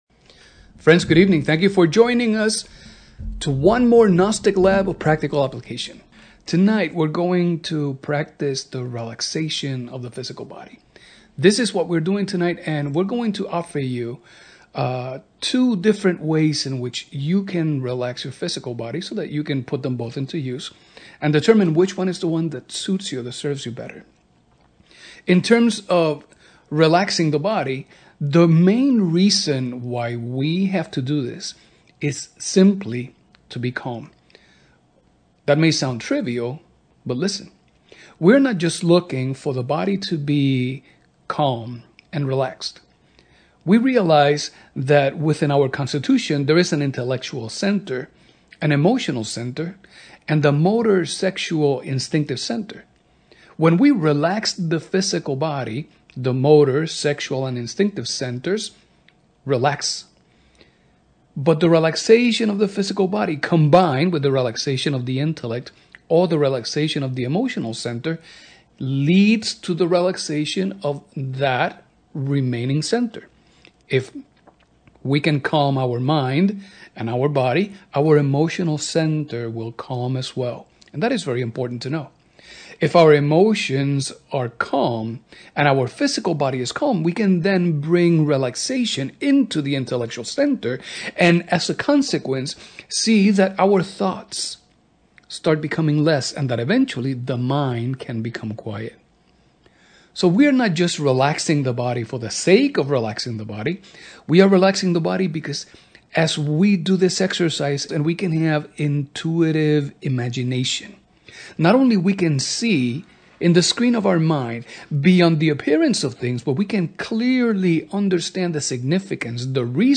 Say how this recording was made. Practice performed live on Tuesdays at 8 pm. (CST)